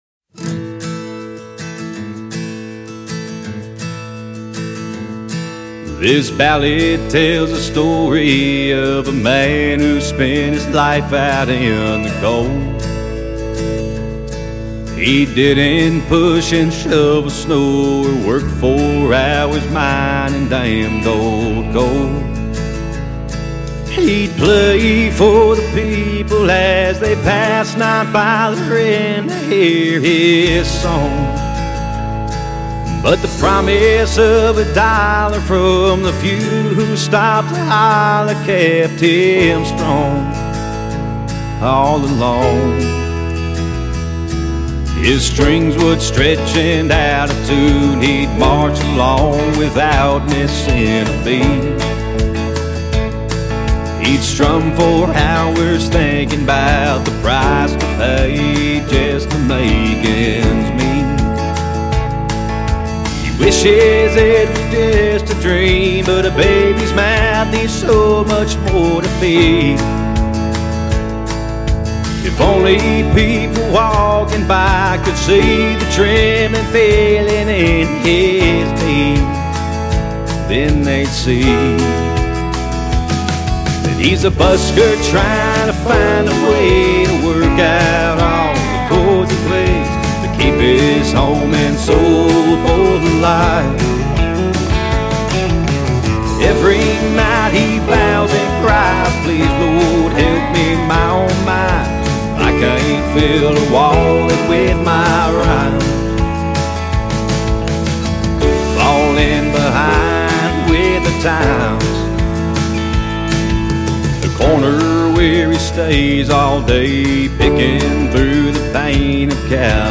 Ballads are quintessential story-telling.
Genre Country